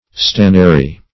Stannary \Stan"na*ry\ (st[a^]n"n[.a]*r[y^]), a. [L. stannum tin,